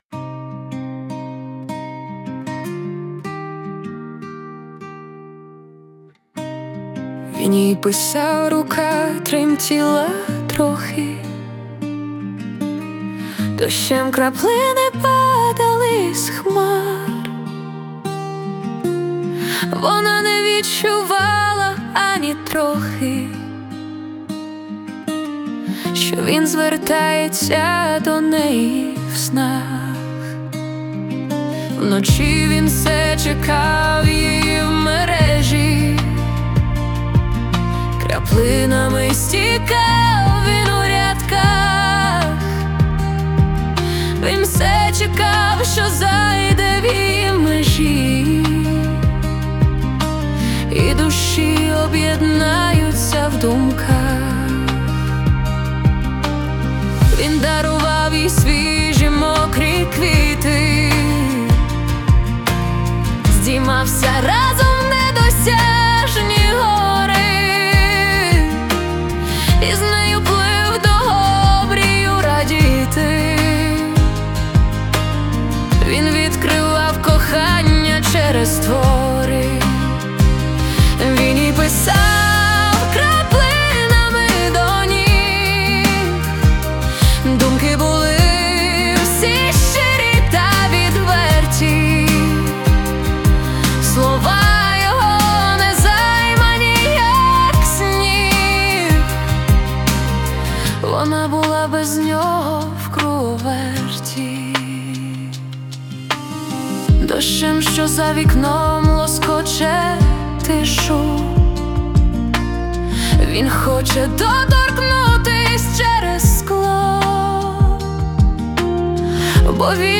Музика і вокал ШІ - SUNO AI v4.5+
СТИЛЬОВІ ЖАНРИ: Ліричний
ВИД ТВОРУ: Пісня